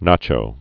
(nächō)